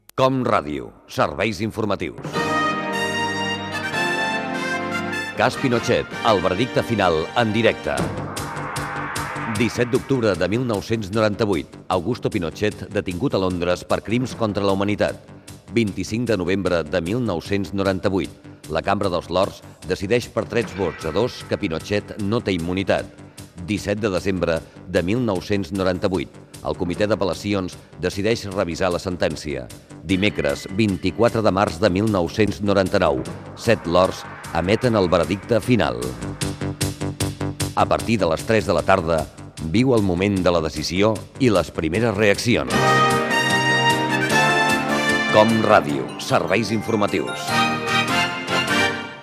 Promoció del programa especial dels Serveis Informatius de COM Ràdio.
Informatiu